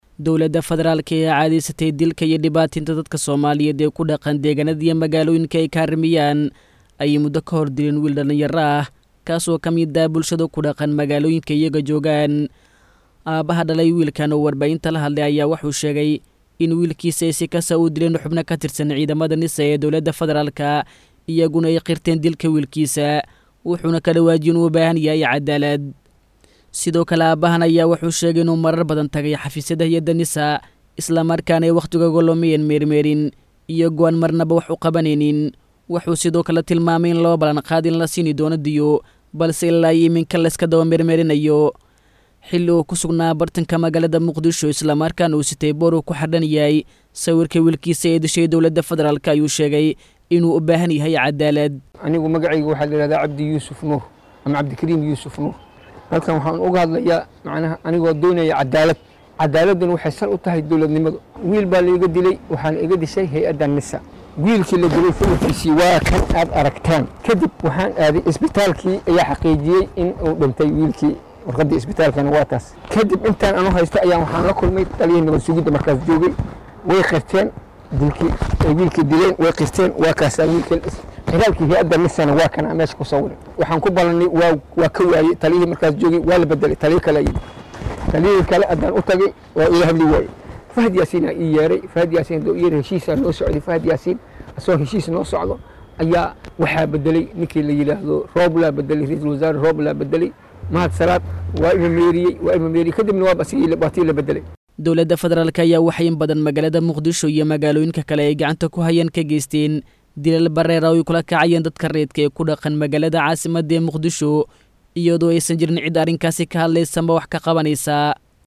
Isagoo wata boor uu ku xardhanyahay sawirka wiilkiisa ay dishey Dowladda Federaalka, ayuu aabahan warbaahinta kula hadlay magaalada Muqdisho.